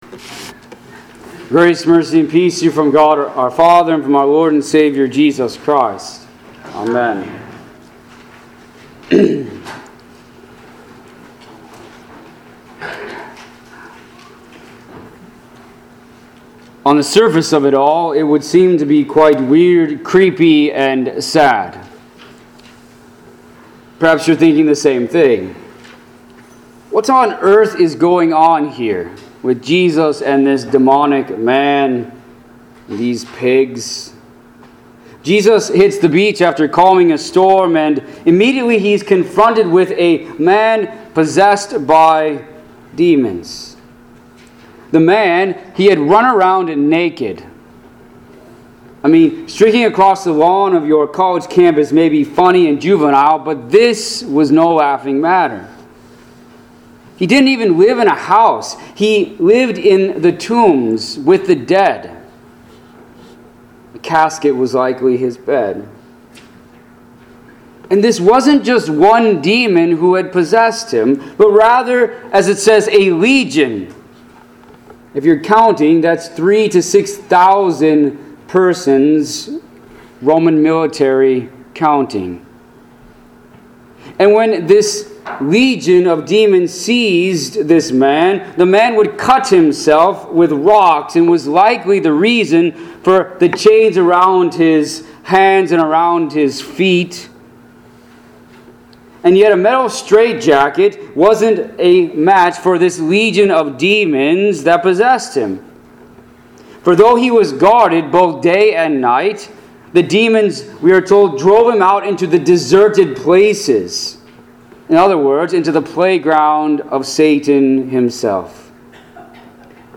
Pilgrim Ev. Lutheran Church - Sermons